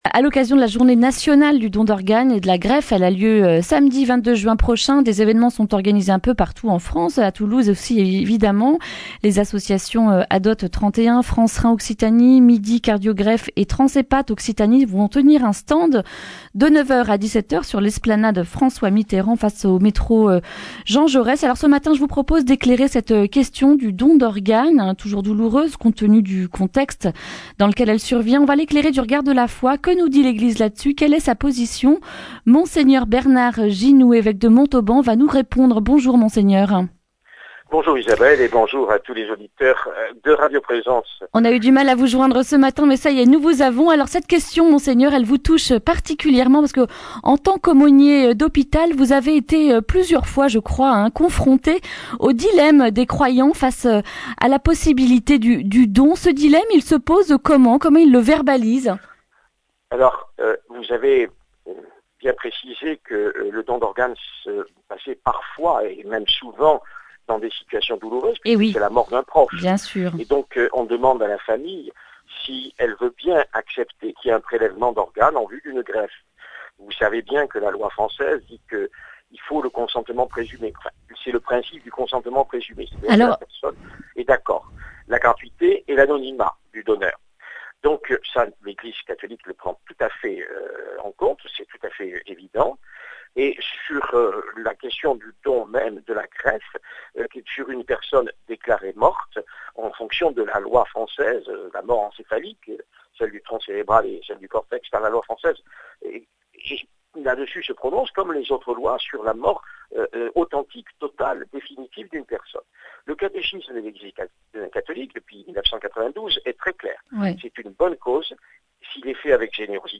jeudi 20 juin 2019 Le grand entretien Durée 11 min